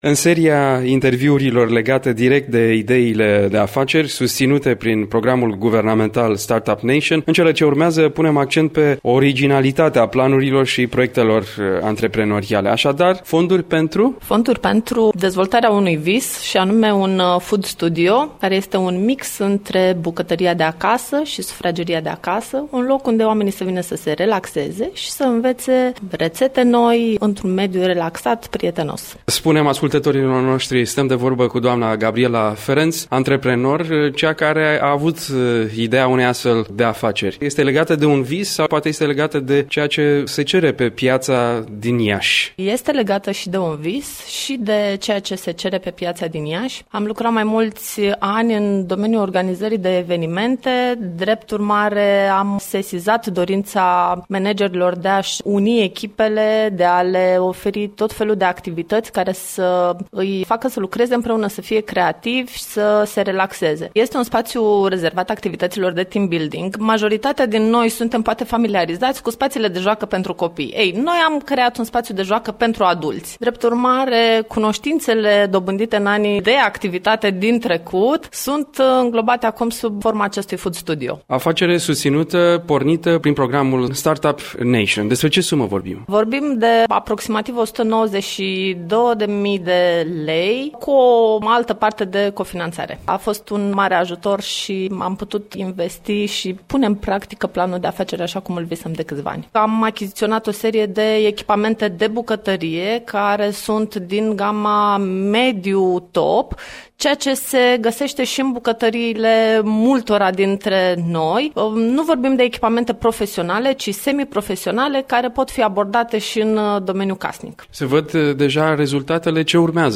Într-o serie de interviuri ce pleacă de la un parteneriat între ministerul de resort și Radio România Regional, la Radio Iași prezentăm reușitele celor care au aplicat pentru a obține fonduri.